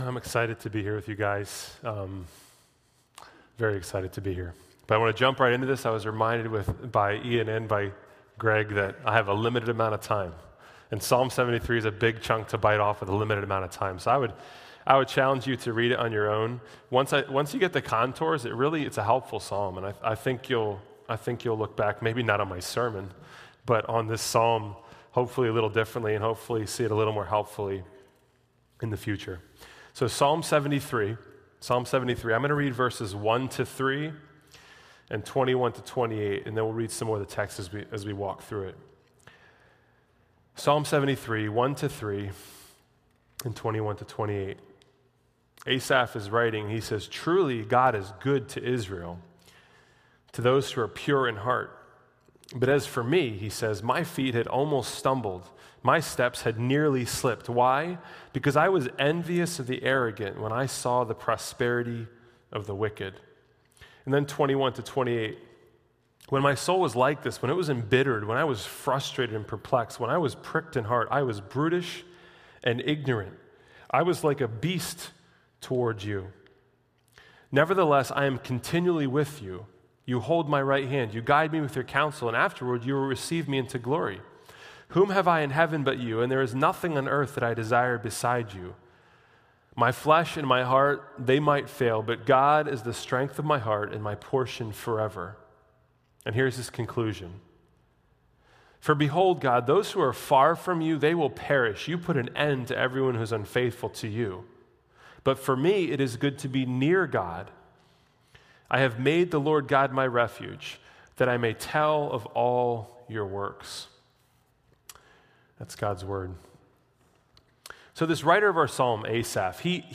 A sermon from the series "The Psalms." God's Word is a living word that brings life!